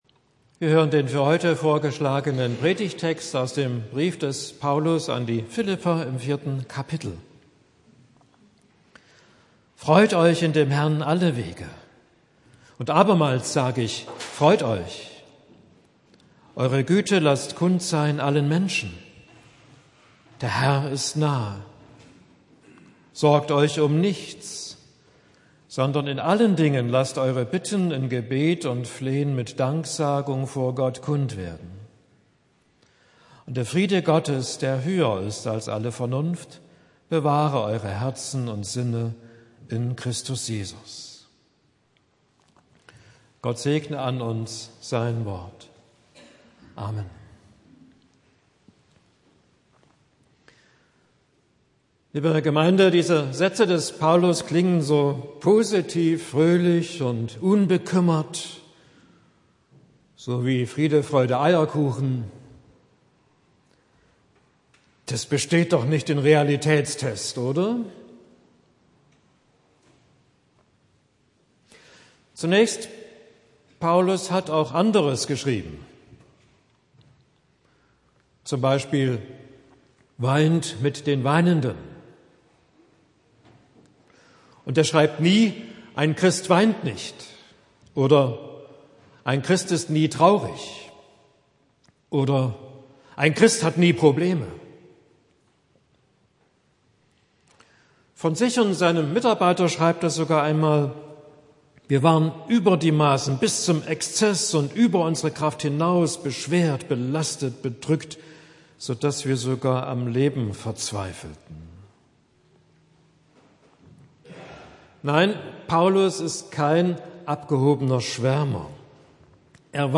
Predigt für den 4. Advent